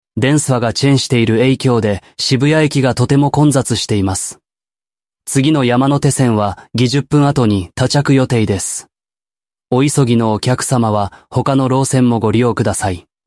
ElevenLabs_Japanese_Dominant_Man.mp3